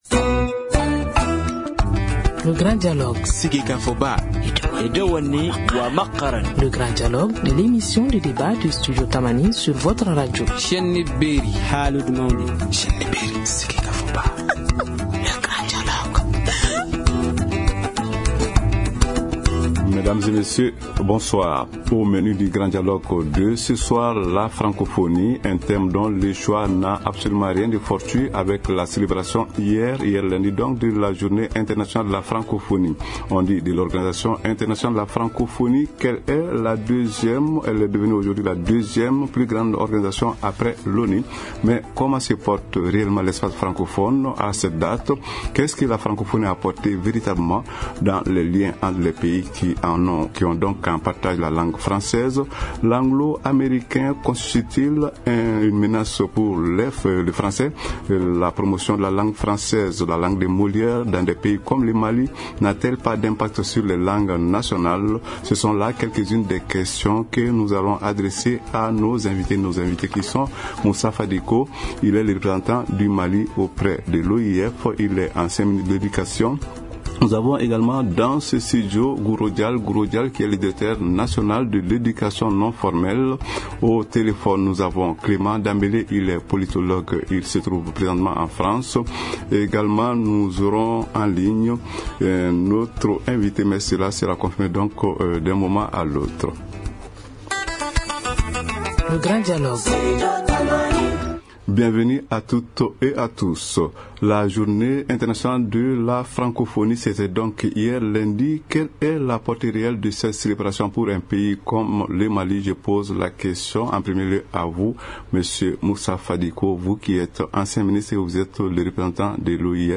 C’est donc à ces questions et à bien d’autres que vont répondre nos invités.